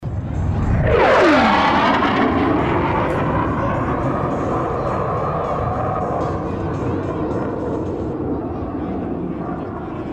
Blue Angels, F/A-18 Hornets,
Flyover at Goodyear Air Show, Phoenix, AZ, October 21, 2006. Some background noise from crowd.